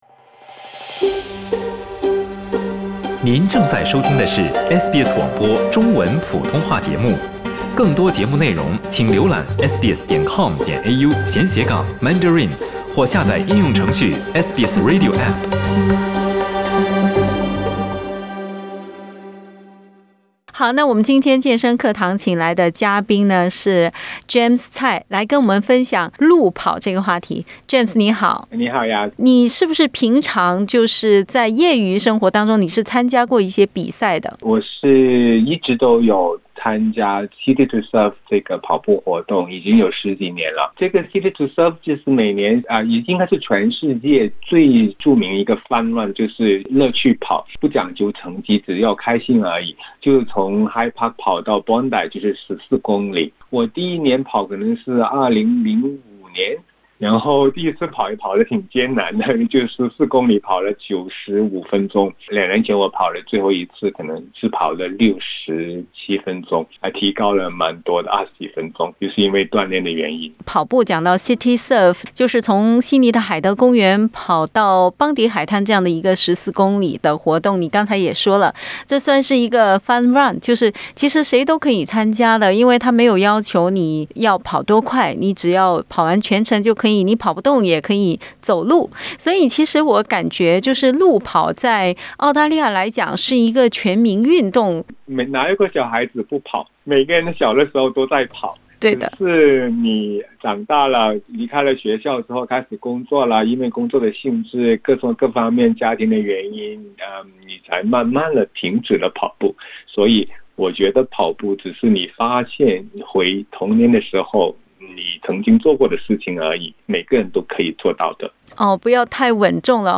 （点击上图收听采访） 温馨提醒想要运动健身的朋友，最好先咨询自己的全科医生，确保你的运动计划适合你的身体条件。